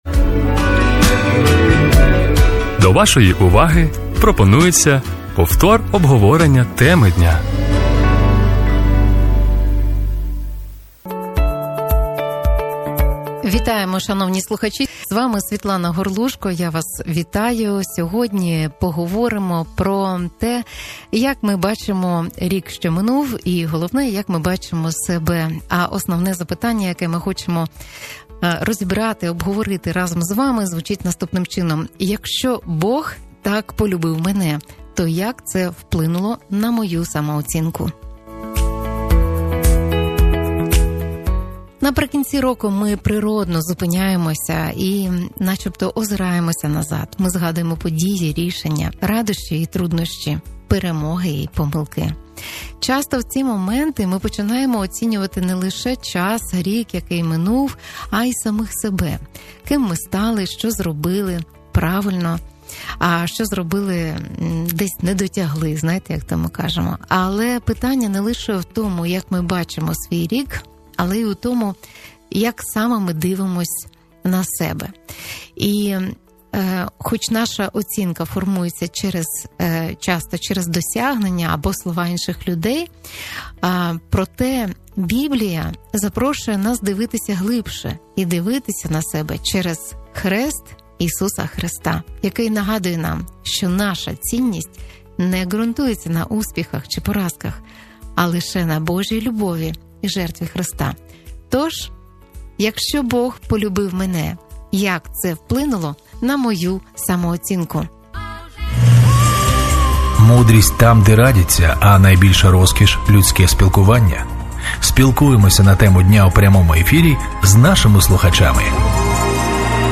Якщо Бог так полюбив мене, то як це впливає на мою самооцінку? У цьому обговоренні почуєте що змінюється в самооцінці після усвідомлення Божого всиновлення.